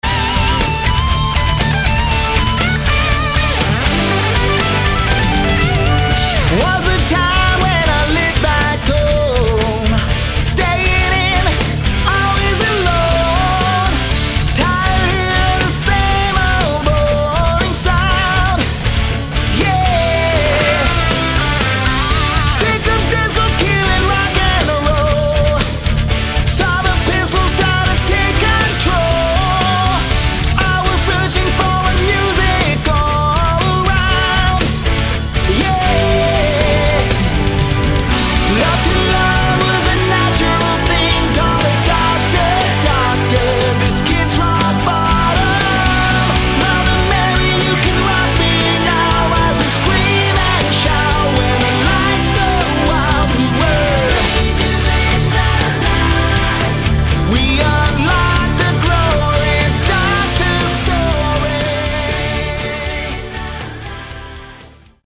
Keyboards and Guitars
Lead vocals
Lead Guitars
Drums
Bass